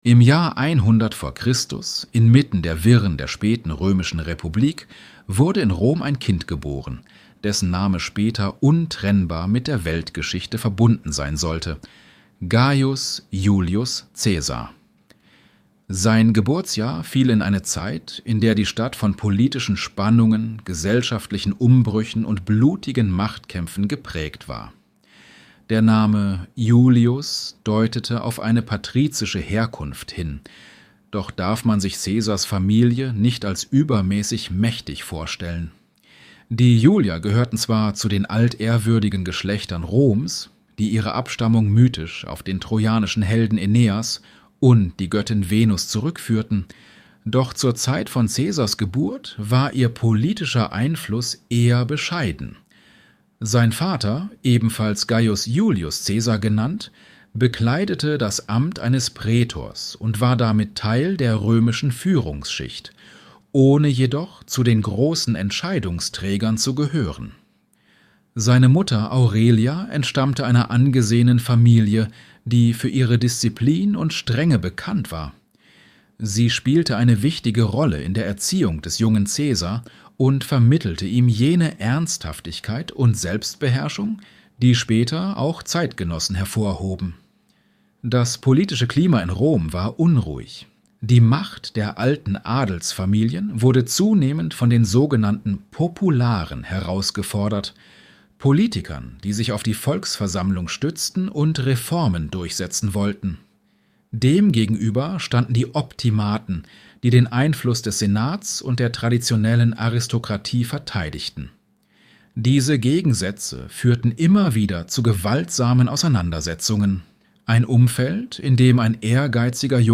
Der Mann, der die Welt eroberte und trotzdem fiel | Julius Cäsar flüstert dir zu